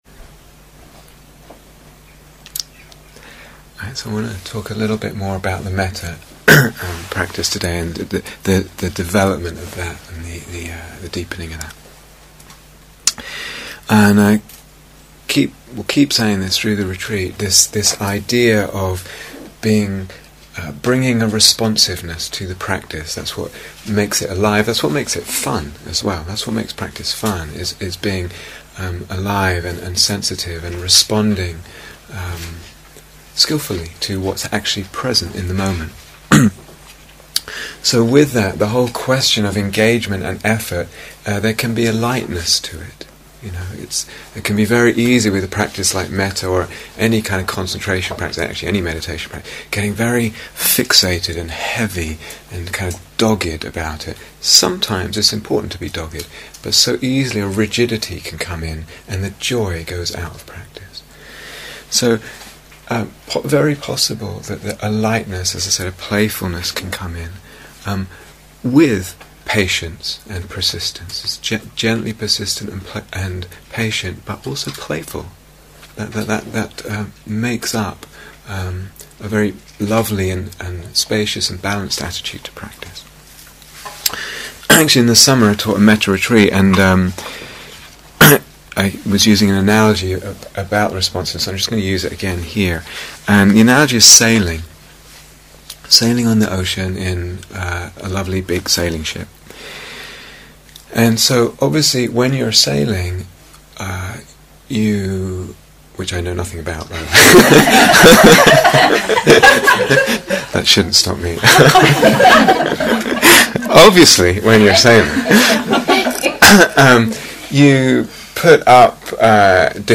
Second Instructions and Guided Mettā Meditation